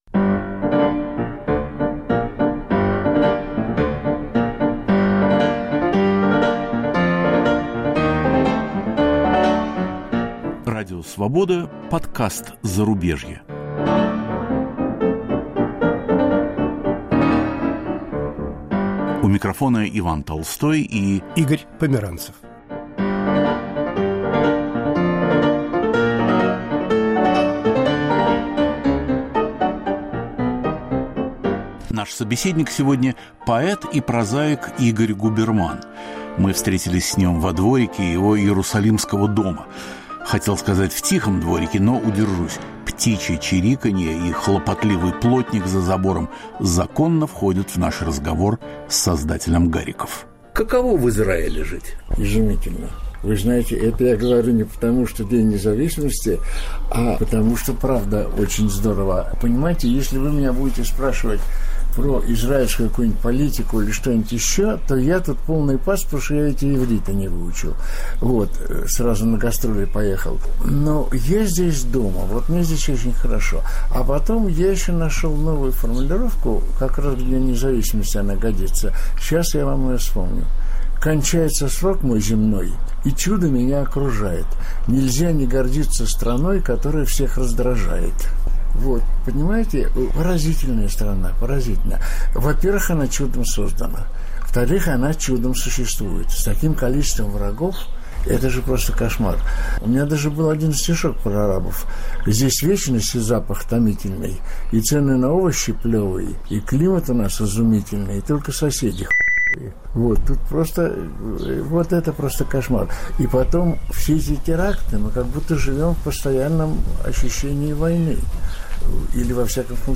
Новый гость подкаста "Зарубежье" – поэт Игорь Губерман, изгнанник с 40-летним стажем. Звучат еще не опубликованные "гарики".